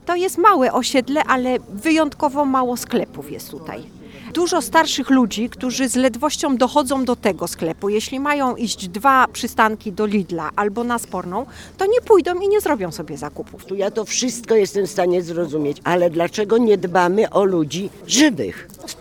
We wtorek (7 marca) w Łodzi odbył się protest mieszkańców przy Przemysłowej.
– To jest małe osiedle, ale i znajduje się na nim wyjątkowo mało sklepów. Starsi mieszkańcy z dużymi problemami docierają do tego sklepu, więc jeśli będą musieli iść 2 przystanki, to w ogóle nie zrobią sobie zakupów – mówi jedna z mieszkanek.
protest-mieszkancow-przy-przemyslowej.mp3